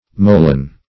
moellon - definition of moellon - synonyms, pronunciation, spelling from Free Dictionary Search Result for " moellon" : The Collaborative International Dictionary of English v.0.48: Moellon \Mo"el*lon\, n. [F.] Rubble masonry.